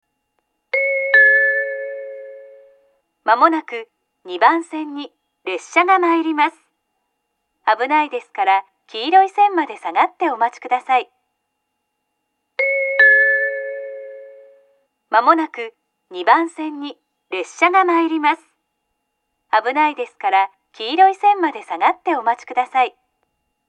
２番線接近放送 上下ともに同じ放送が流れます。